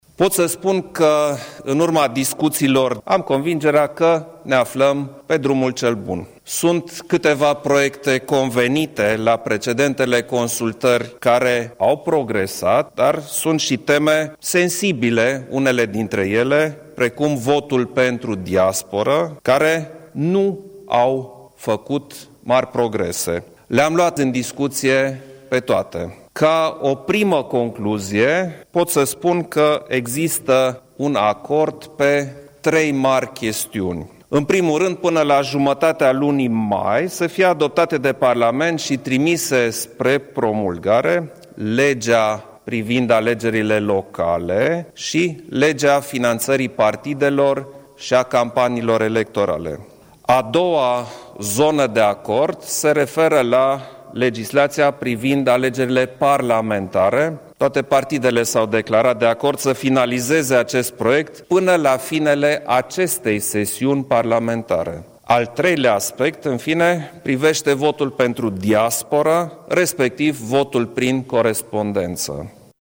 Președintele Klaus Iohannis a declarat că, în urma discuțiilor cu reprezentanții partidelor politice parlamentare, Legea electorală va ajunge în luna mai pe masa președintelui pentru promulgare: